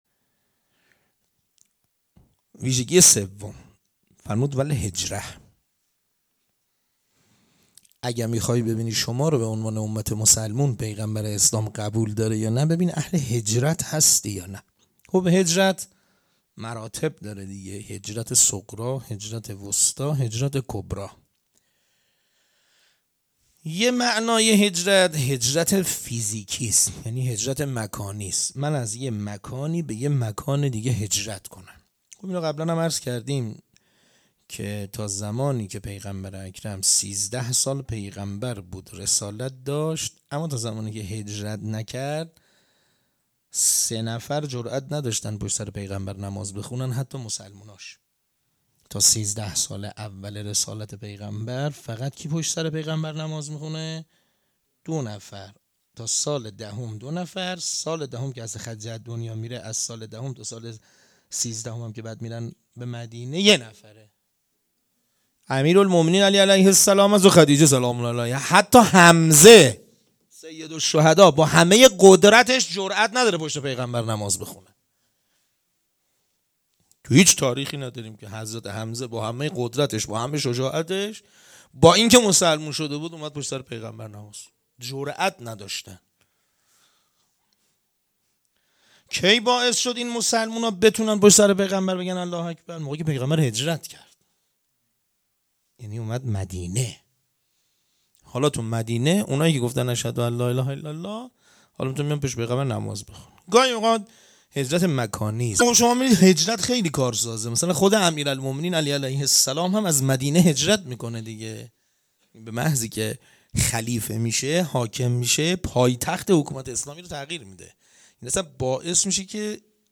صوت سخنرانی حجت السلام و المسلمین